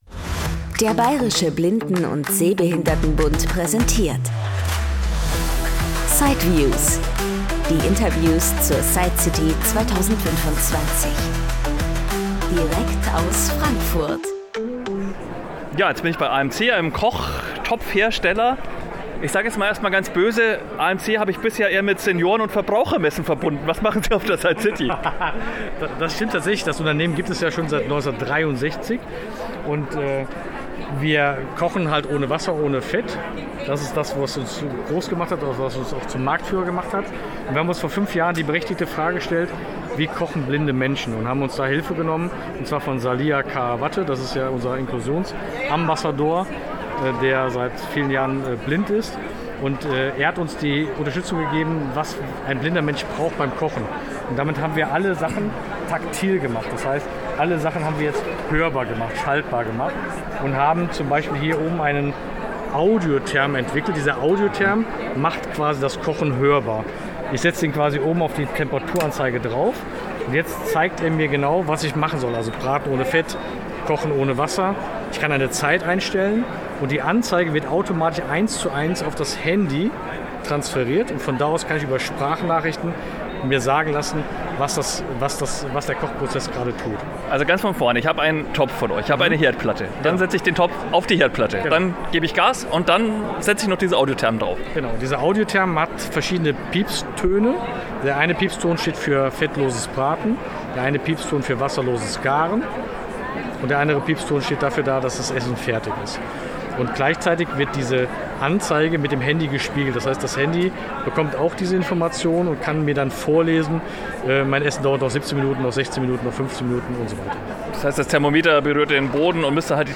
Im Interview gibt’s darüber hinaus jede Menge Humor,